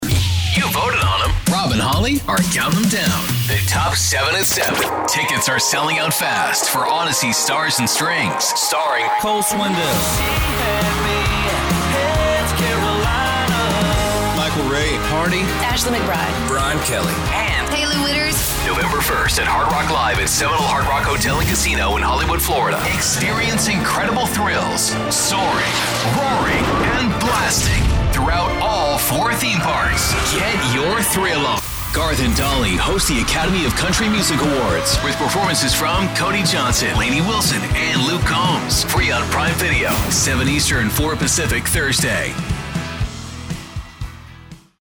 Imaging Voice Demos: